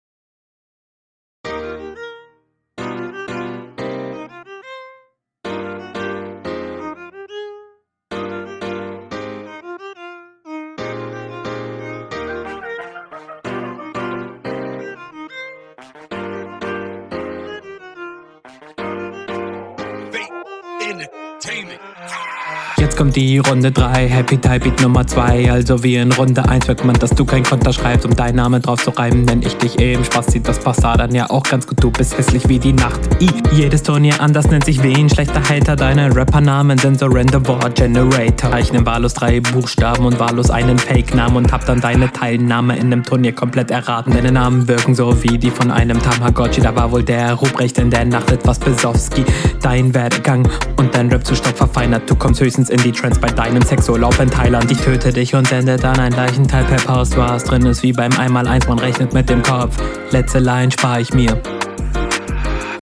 du bist leider noch viel zu stiff :/.